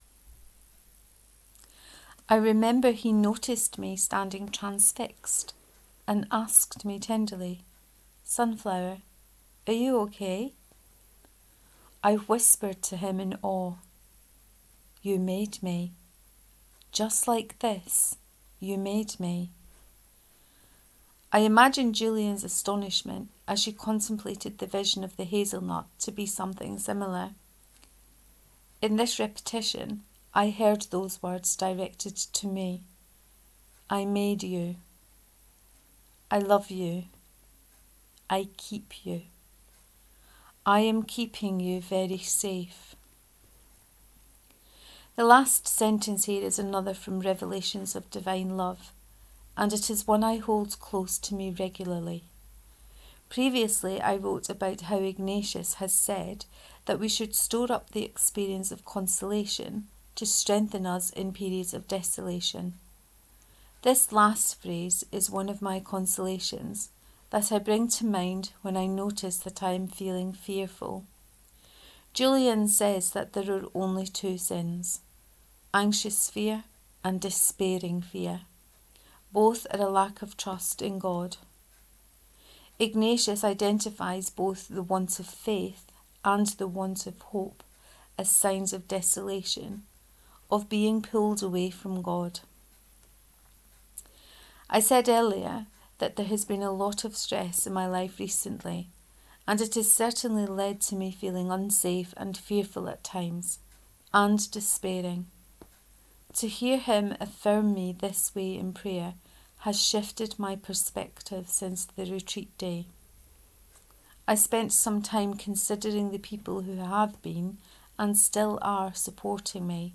Reading of All things in a hazelnut part 4